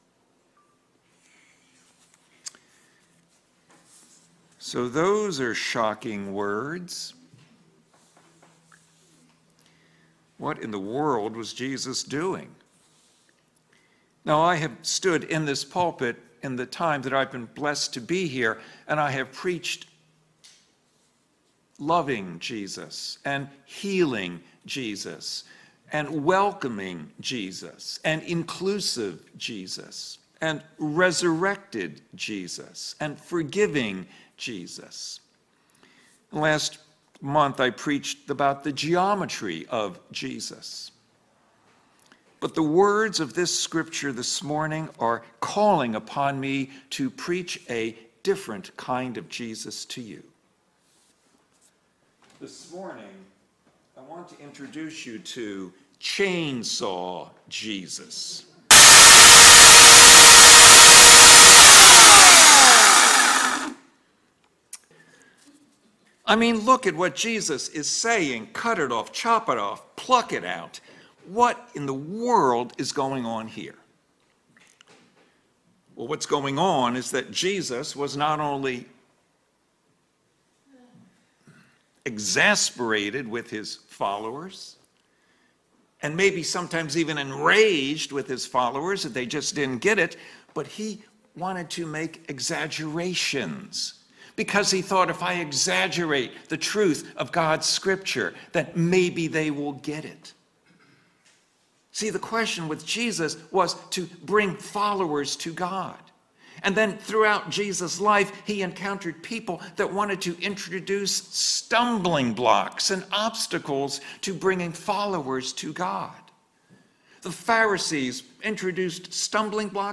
Sermons | Royal Oak Community UMC